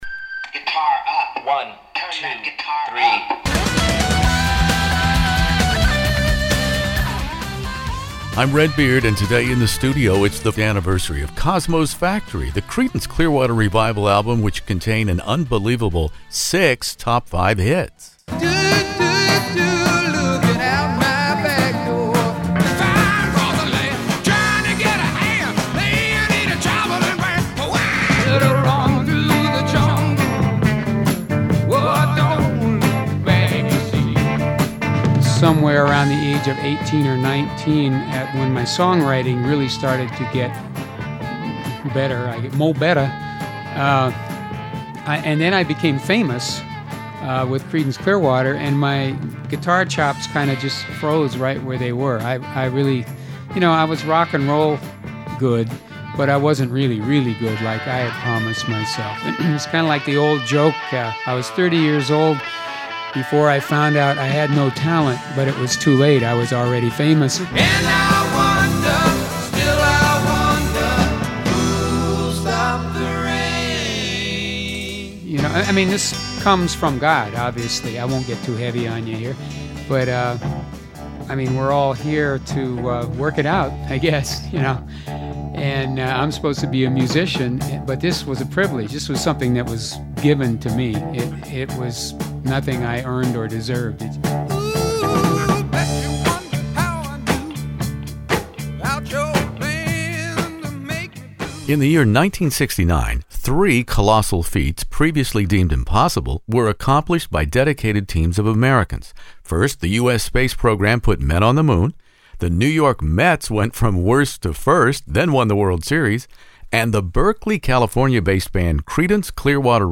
Enjoy my very rare classic rock interview on Cosmo’s Factory 55th anniversary.